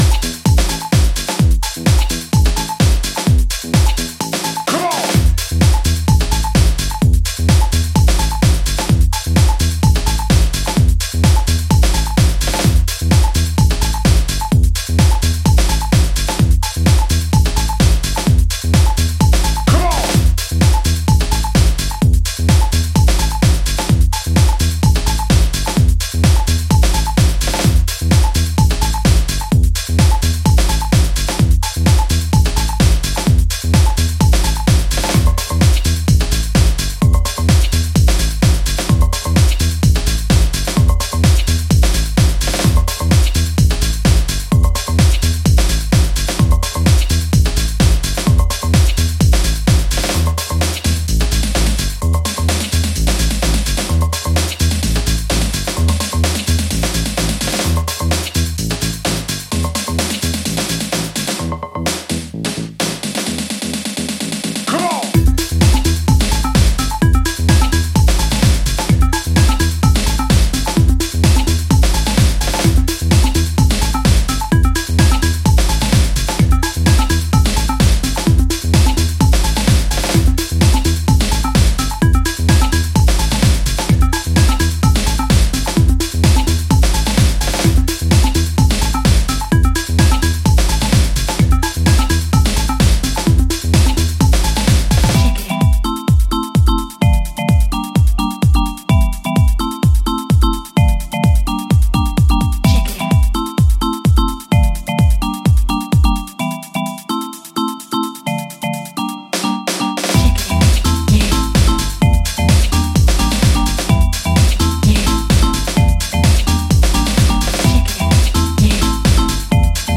ソリッドに跳ねるニュースクール・テック・ハウスを展開しており